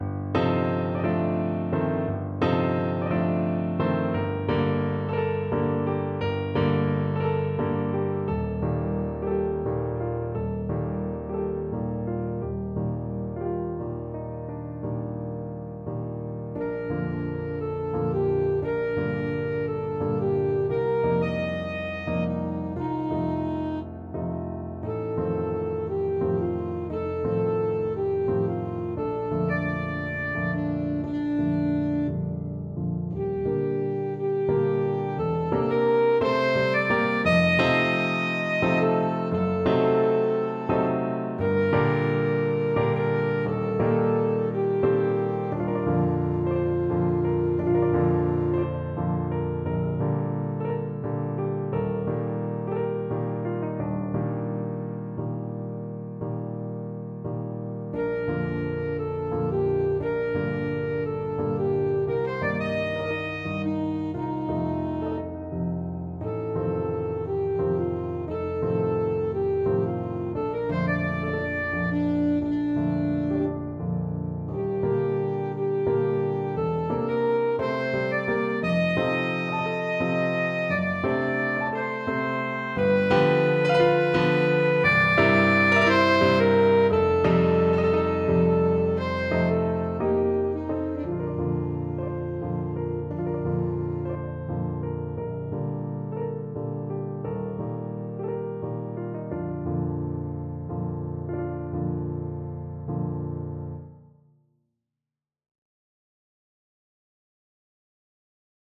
Alto Saxophone version
Alto Saxophone
6/8 (View more 6/8 Music)
Classical (View more Classical Saxophone Music)